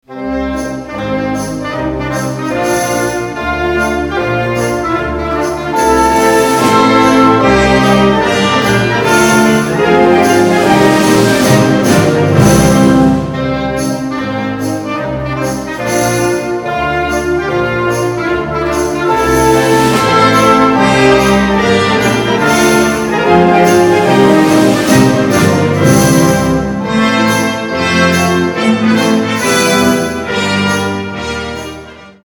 Kategorie Blasorchester/HaFaBra
Unterkategorie Suite